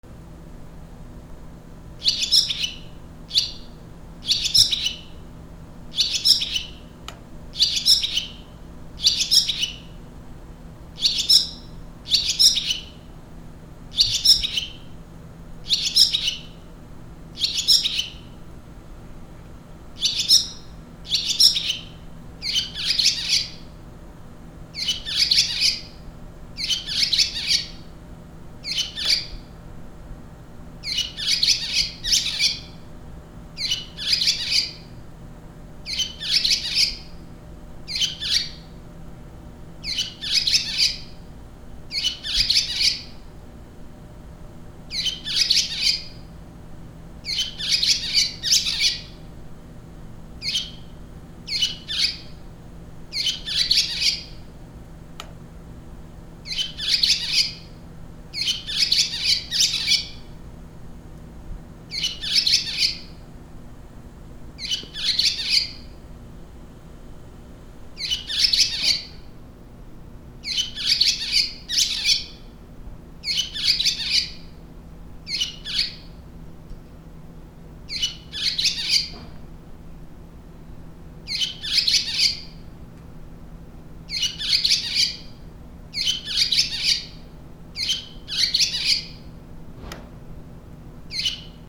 ツバメ
/ D｜動物 / D-05 ｜鳥 / 60｜ツバメ
『キュキュ』